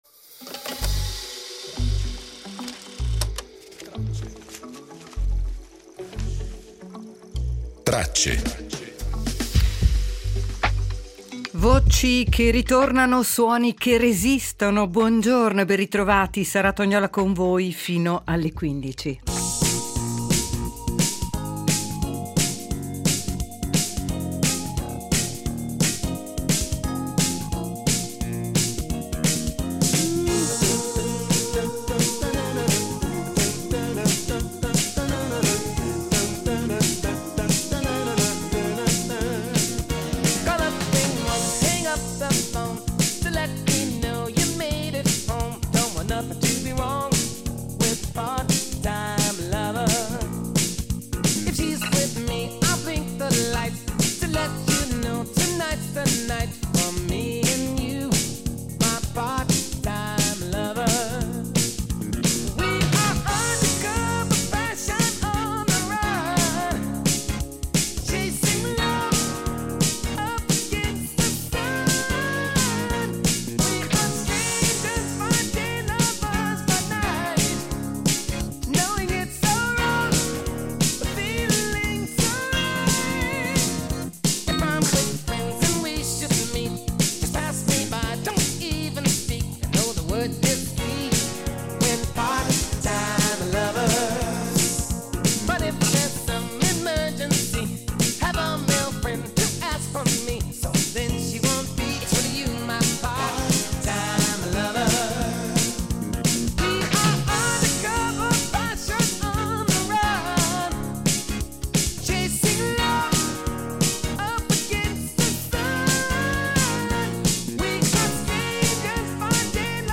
Nella puntata di oggi, si torna alla storica visita di papa Giovanni Paolo II a Cuba , raccontata dalla radio in giorni carichi di attese, tensioni e delicati equilibri diplomatici. Le voci dell’archivio rievocano Lenin , ripercorrendo le origini e le contraddizioni di una delle figure più centrali e controverse del Novecento, attraverso sguardi e interpretazioni che ancora oggi interrogano la storia europea e globale.